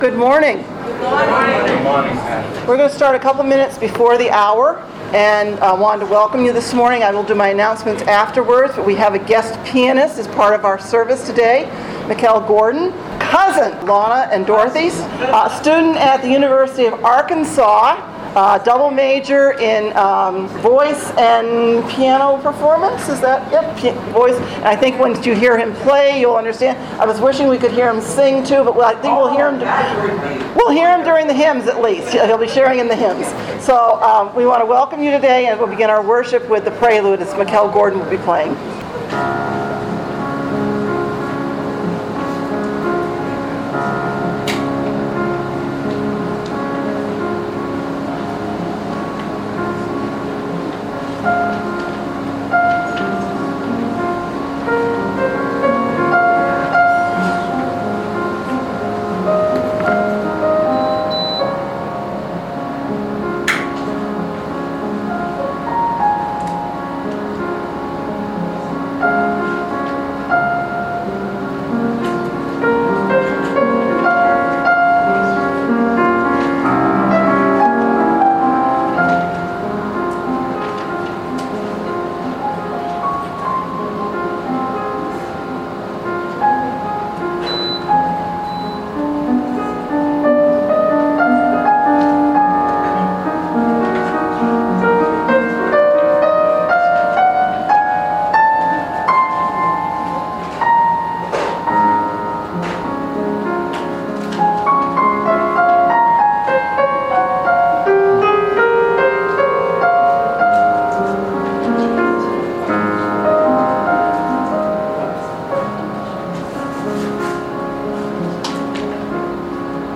Worship Service August 4, 2019 | First Baptist Church, Malden, Massachusetts